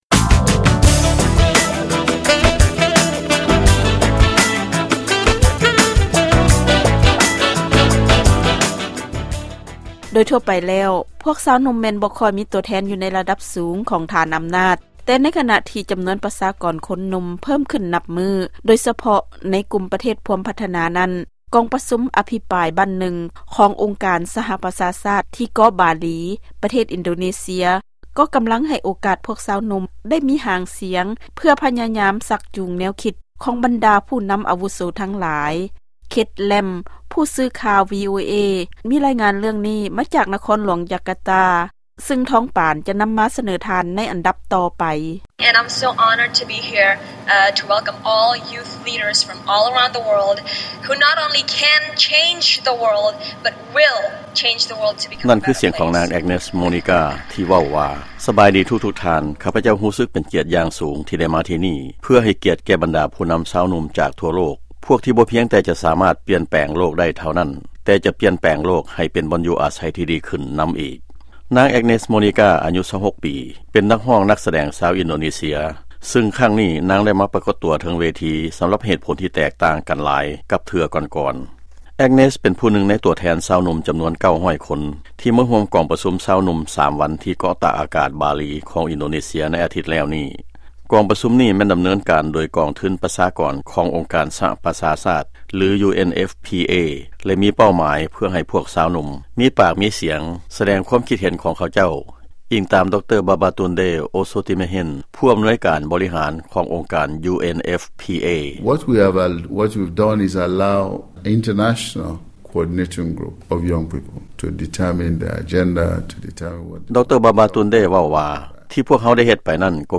ຟັງລາຍງານກອງປະຊຸມຊາວໜຸ່ມທີ່ອິນໂດເນເຊຍ